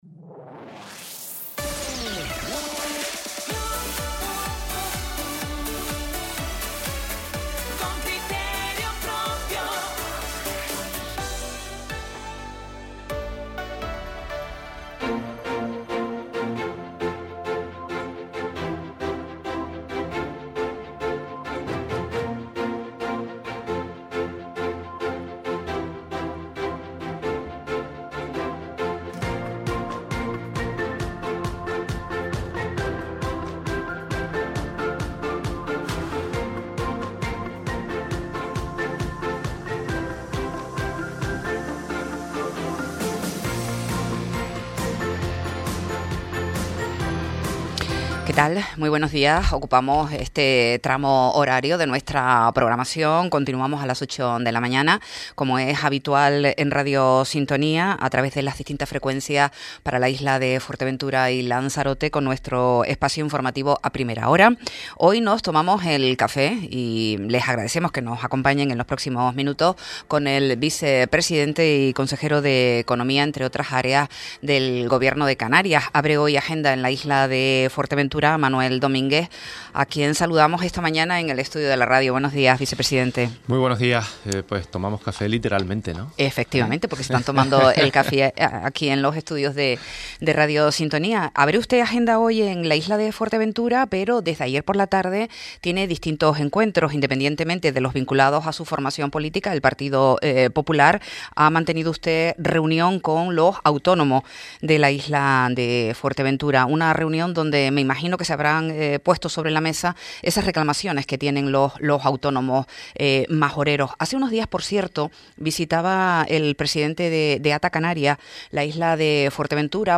Entrevista a Manuel Domínguez, vicepresidente y consejero de Economía del Gobierno de Canarias - 03.12.25 - Radio Sintonía